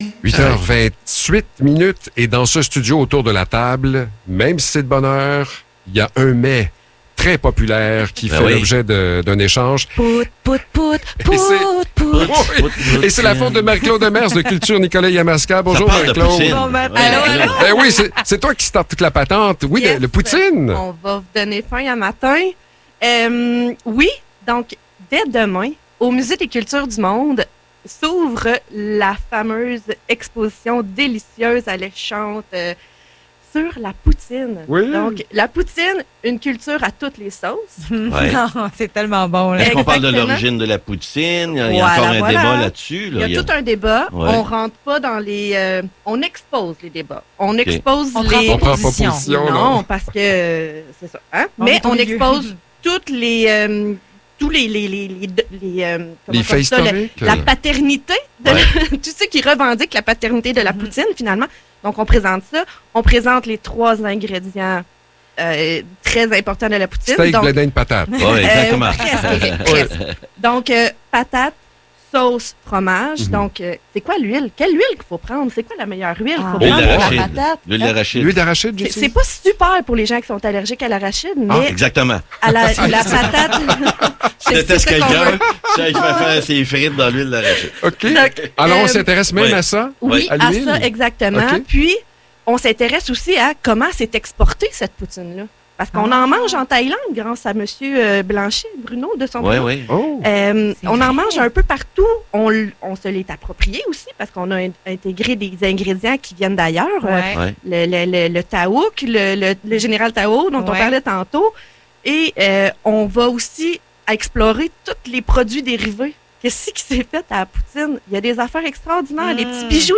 Chronique culturelle Nicolet Yamaska